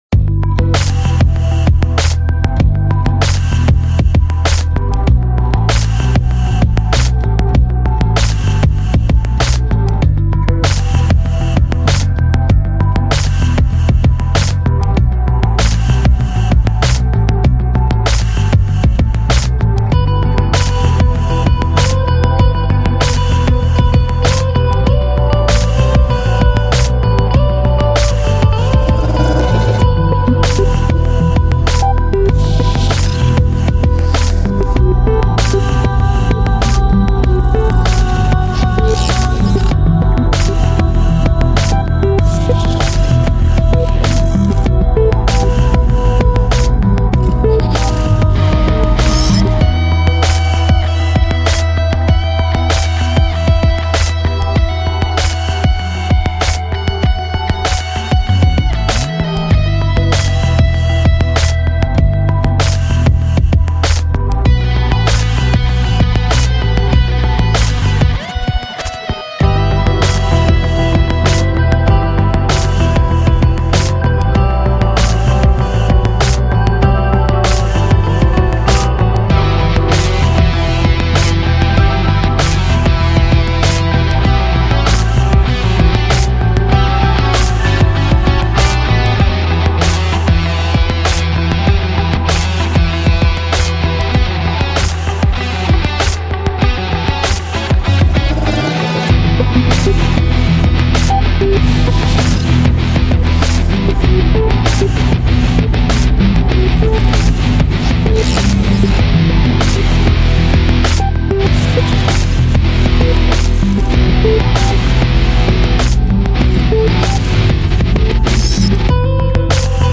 really cool liquid sounding electronic song base